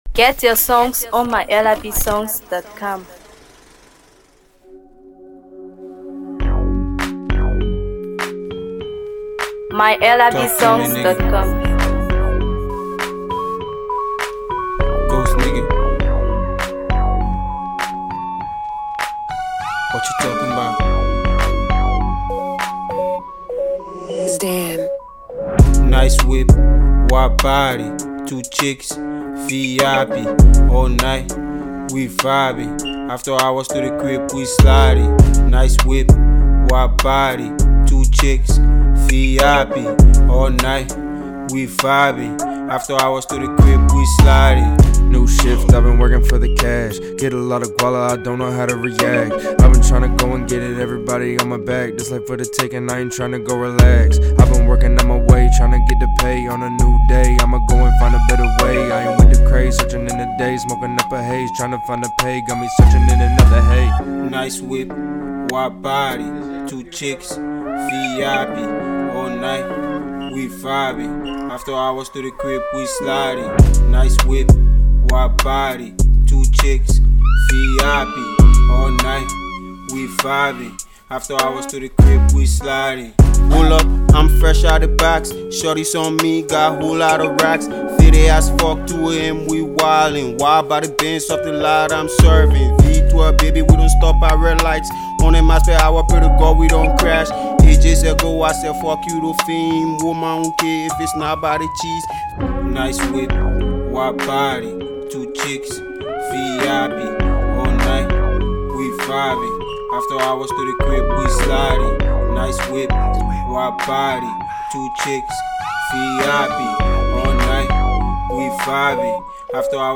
Hip Hop
catchy rhythm, bold delivery